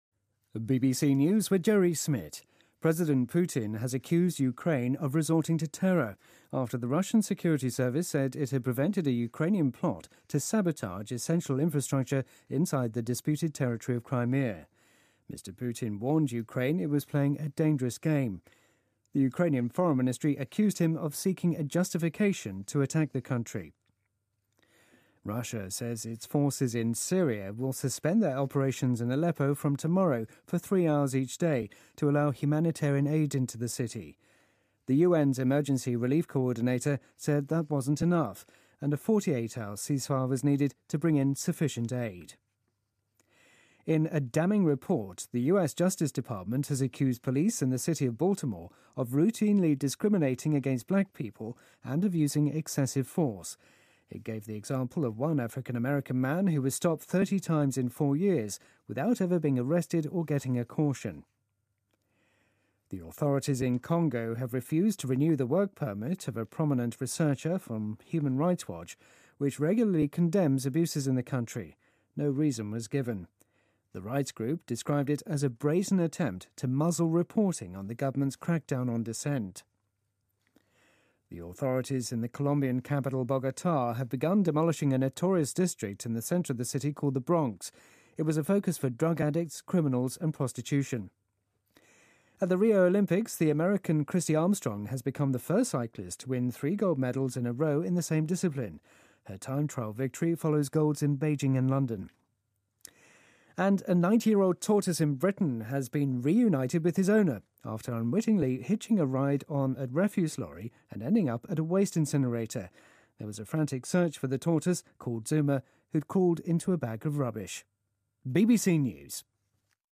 BBC news,普京警告乌克兰莫玩火自焚